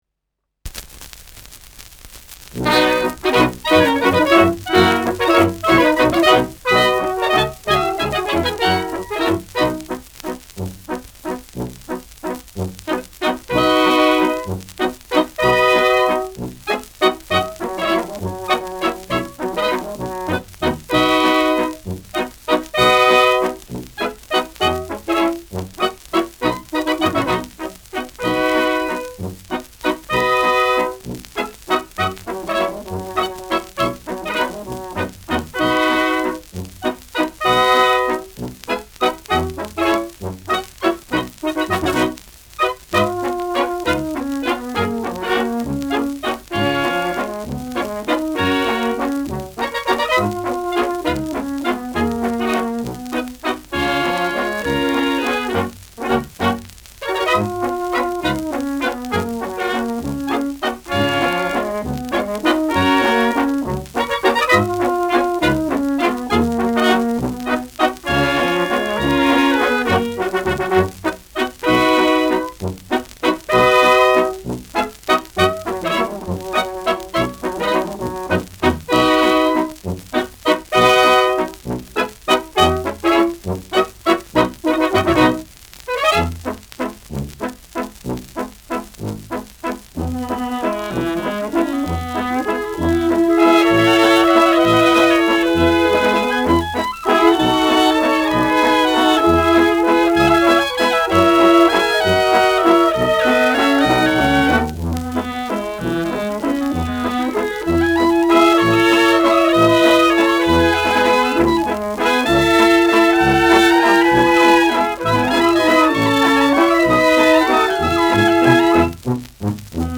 Schellackplatte
leichtes Knistern
[Wien] (Aufnahmeort)